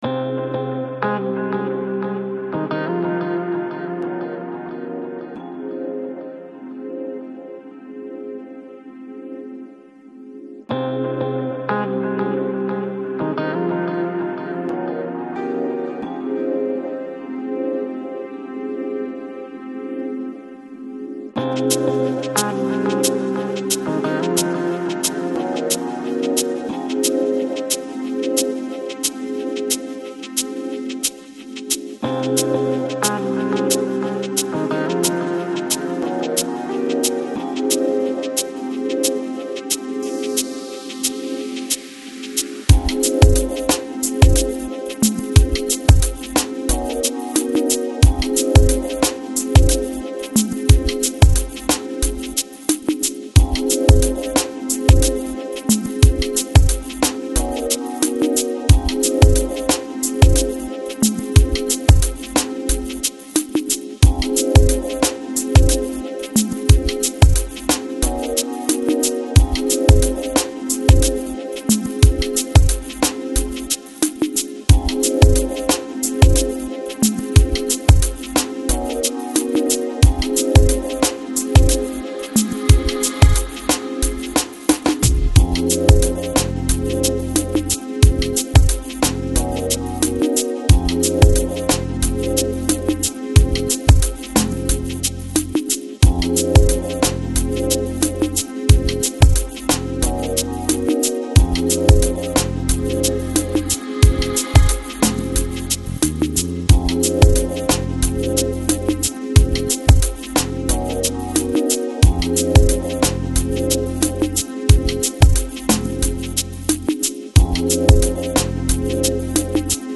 Жанр: Chill Out, Lounge